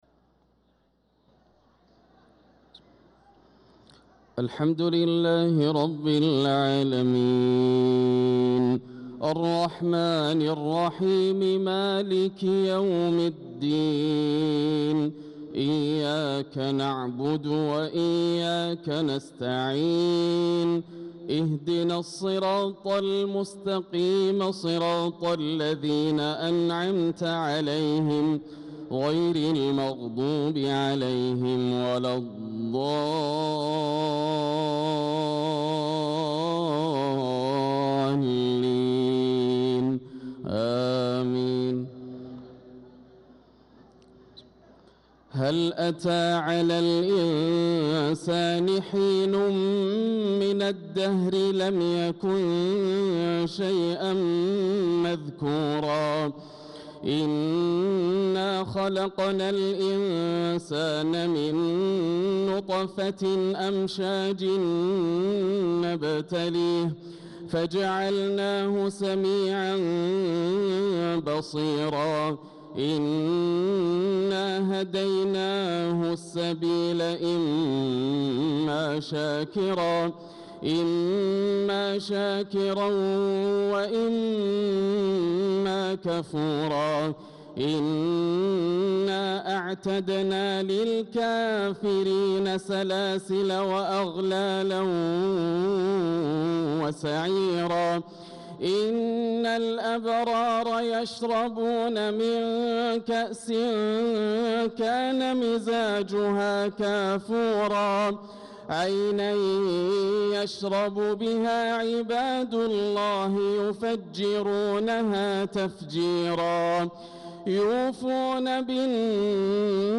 صلاة الفجر للقارئ ياسر الدوسري 14 ذو الحجة 1445 هـ
تِلَاوَات الْحَرَمَيْن .